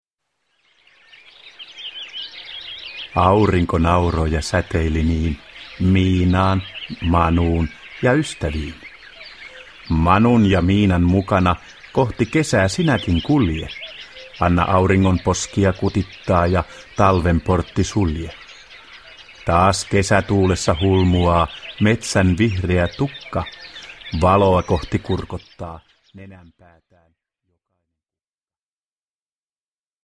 Miinan ja Manun riimejä ja loruja – Ljudbok – Laddas ner